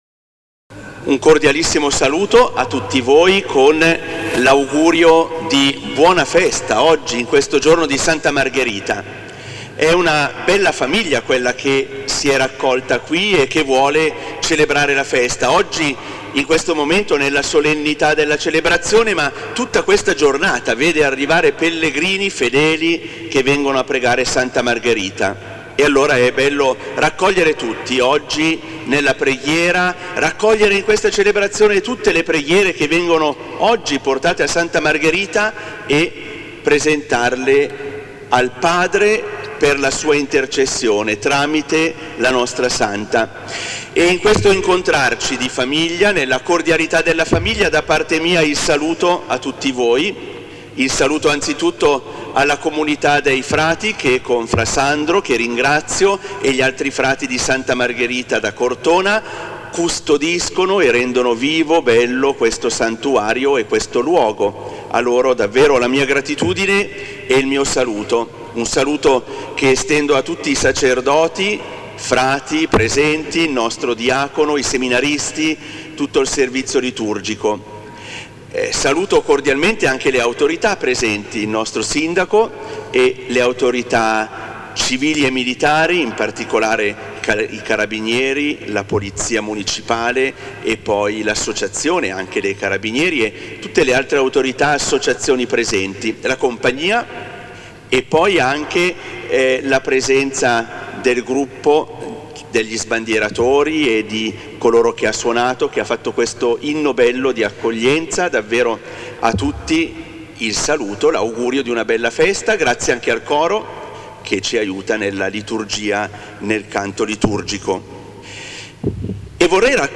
"Omelia del Vescovo" - SS Messa da Santa Margherita del 22 febbraio 2025 - Radio Incontri inBlu Cortona
Omelia di S.E. Vescovo Mons. Andrea Migliavacca – SS Messa al santuario di Santa Margherita di Cortona Su questa pagina è disponibile il podcast dell’evento, un’occasione imperdibile per chi desidera ascoltare e lasciarsi ispirare dalle parole del Vescovo di Arezzo – Cortona – Sansepolcro Mons.